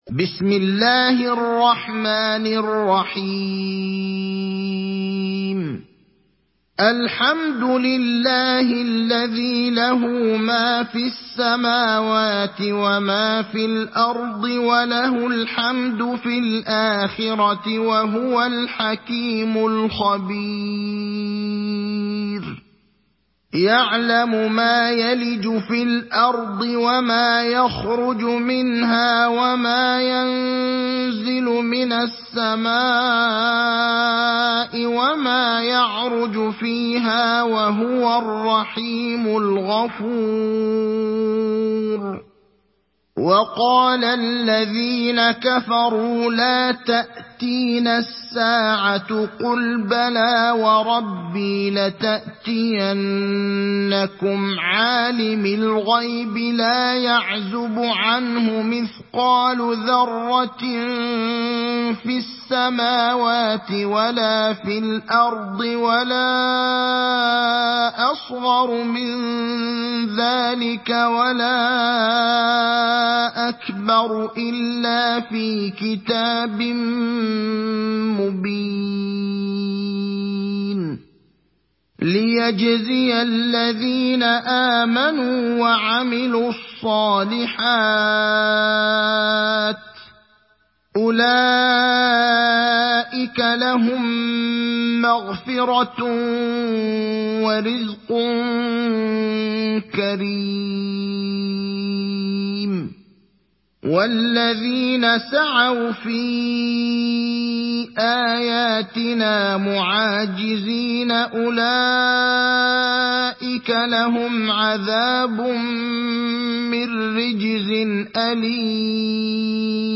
Surat Saba mp3 Download Ibrahim Al Akhdar (Riwayat Hafs)